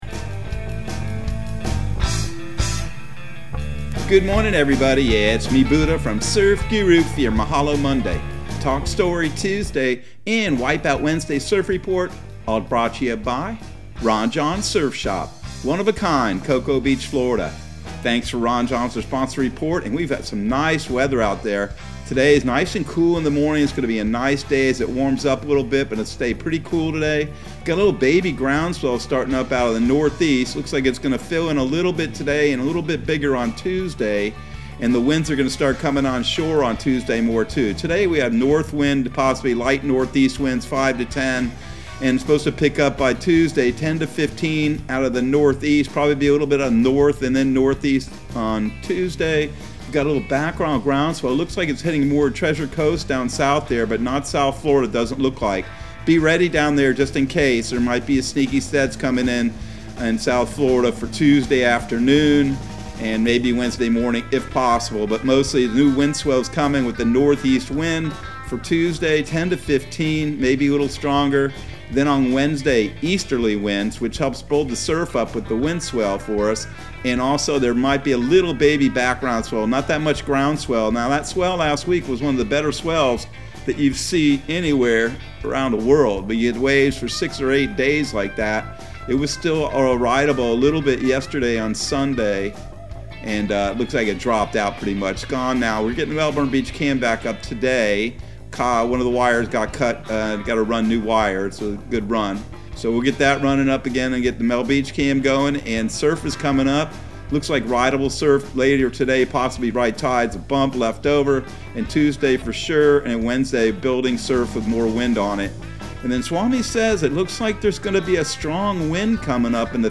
Surf Guru Surf Report and Forecast 11/15/2021 Audio surf report and surf forecast on November 15 for Central Florida and the Southeast.